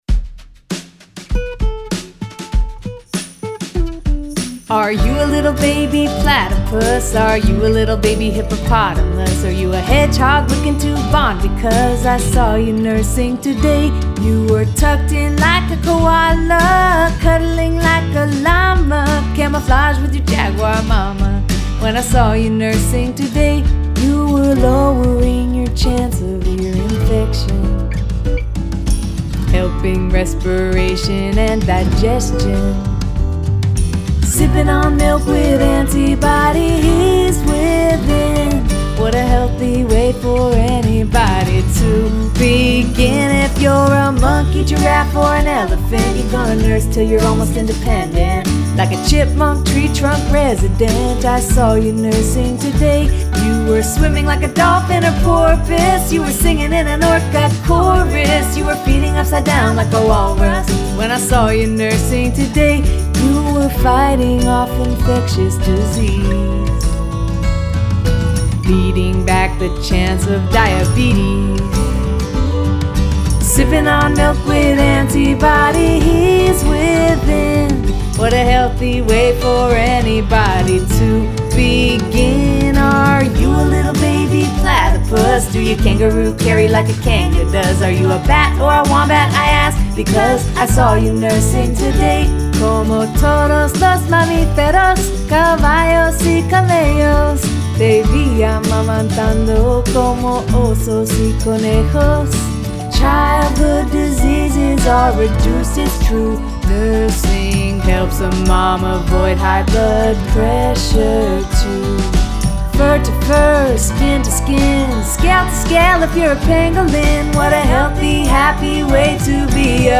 folksong